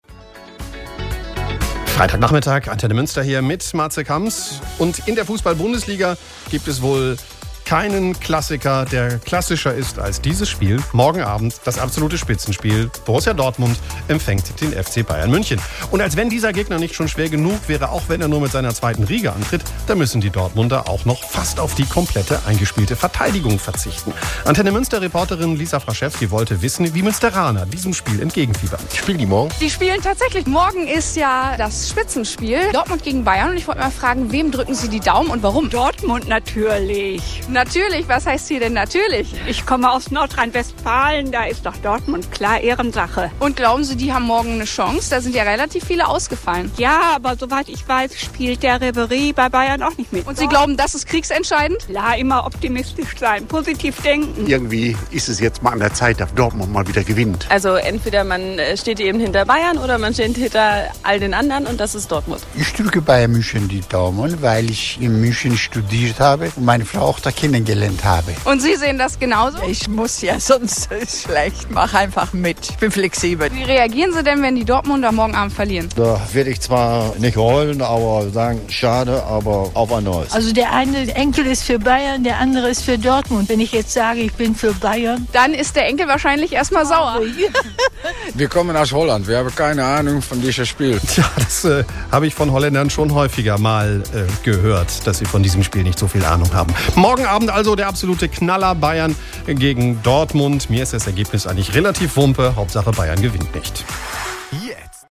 Das Spitzenspiel Borussia Dortmund gegen Bayern München stand vor der Tür und ich habe auf der Straße nachgefragt, wem die Münsteraner:innen die Daumen drücken:
Geführte-Umfrage-zum-Spitzenspiel.mp3